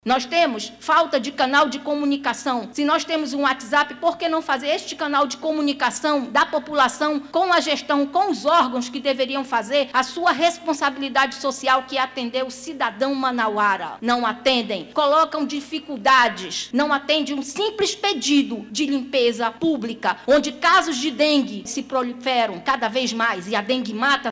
A Câmara Municipal de Manaus – CMM realizou nesta quarta-feira 17/04, uma Tribuna Popular para receber as demandas dos moradores da zona Centro-Oeste da capital amazonense.